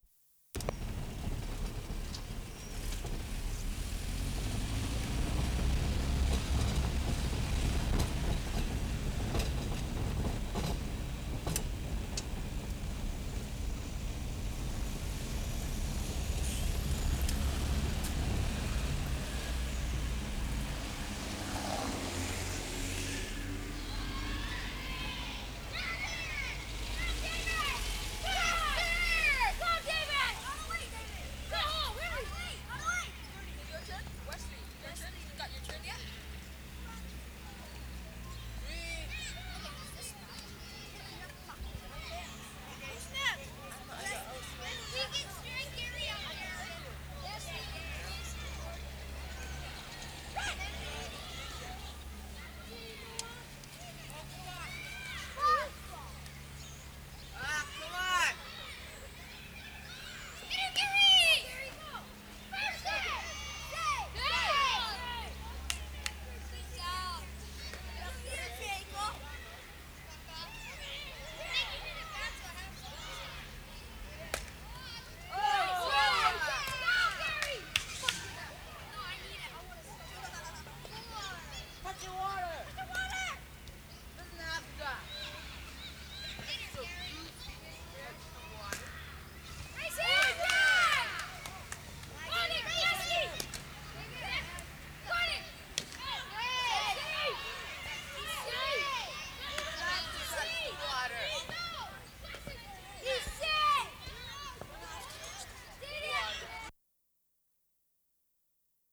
BASEBALL GAME 1'45"
7. Starts out with lots of traffic. Boys voices very lively, echoey, must be surrounded by buildings. Traffic ambience and urban roar throughout.